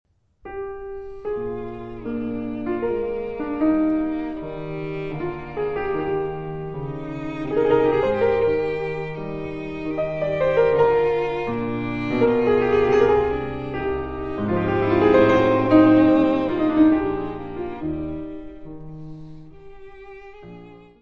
: stereo; 12 cm + folheto
violino
Music Category/Genre:  Classical Music
Sonata for violin and piano